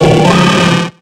Cri de Kicklee dans Pokémon X et Y.